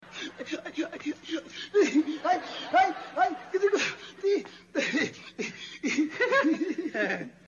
risa-don-ramon-3.mp3